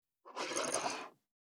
390,机の上をスライドさせる,スー,ツー,サッ,シュッ,スルッ,ズズッ,スッ,コト,トン,ガタ,ゴト,カタ,ザッ,ヌルッ,キュッ,ギギッ,シャッ,スリッ,ズルッ,シャー,
効果音